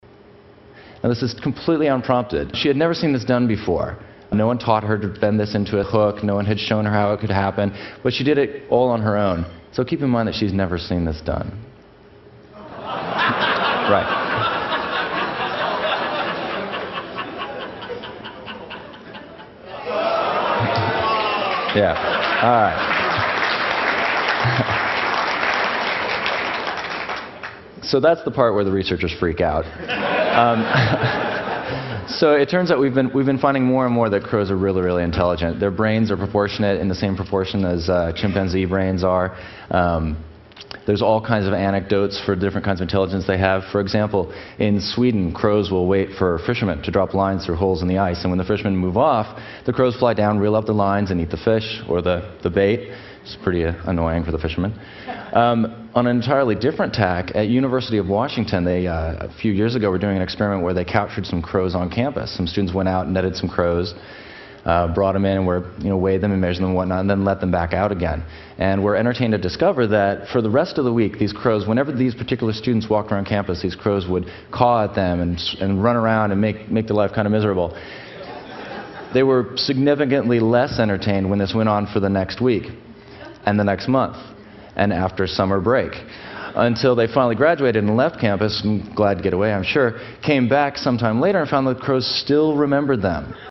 TED演讲:谈乌鸦的智慧(3) 听力文件下载—在线英语听力室